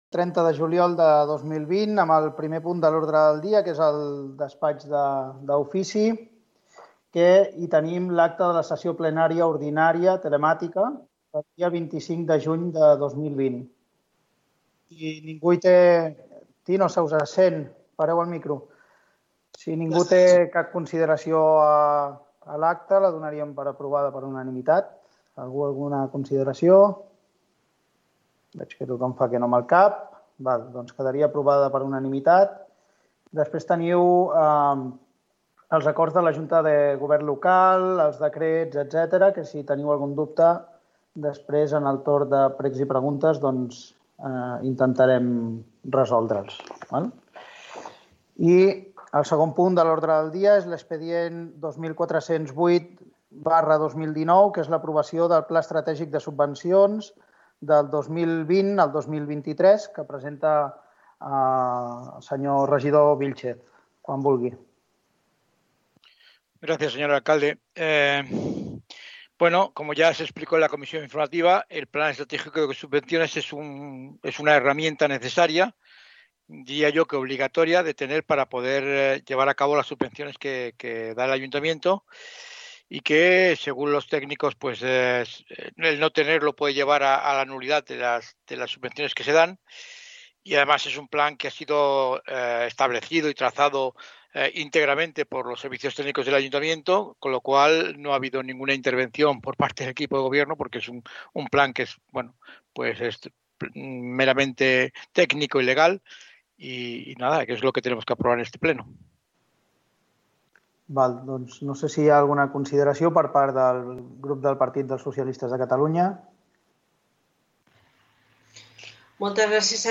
Transmissió del Ple Municipal de l'Ajuntament de Sentmenat, presidit per l'alcalde Marc Verneda Urbano amb la presentació del Pla Estratègic de Subvencions i la seva votació
Informatiu